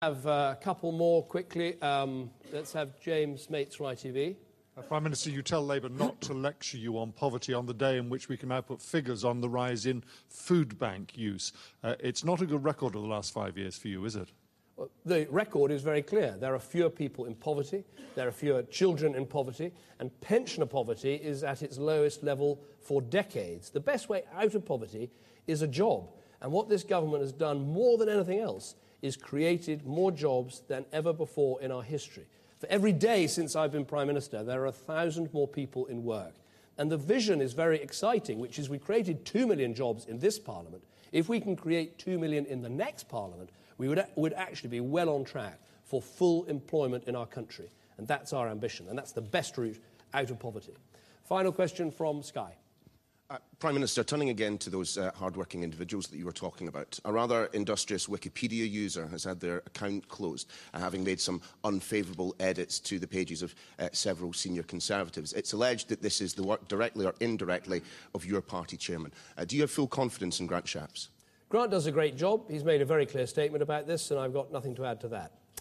The Prime Minister answering journalists' questions in Bedford, 22 April 2015